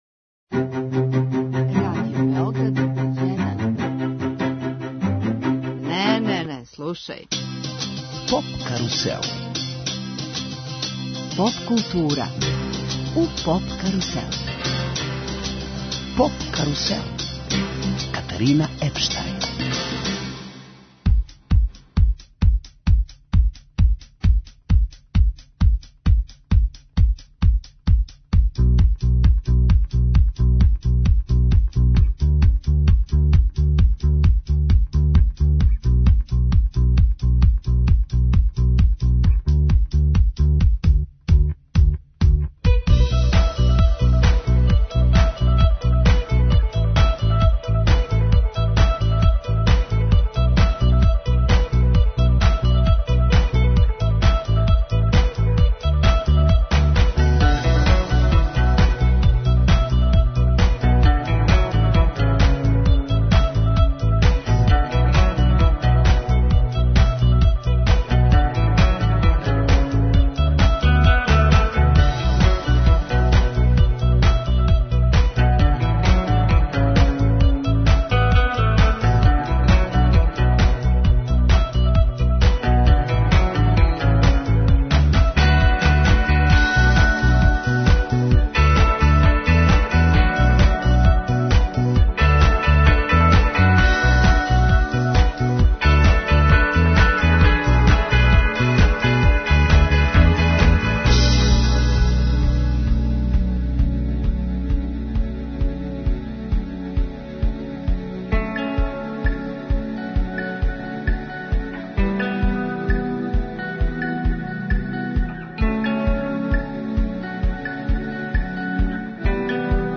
Директно из Гронингена, емитујемо програм посвећен номинованим извођачима за највећу европску музичку награду.